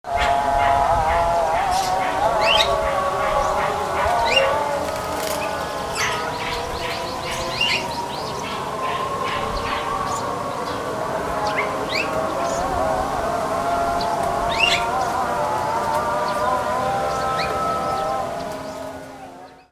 In the Sumatran town of Bukittinggi, surrounded by volcanoes.
At praying hours a deafening cacophony rises as every caller tries to drown out and outshine the others. Birds are excited and join in the racket: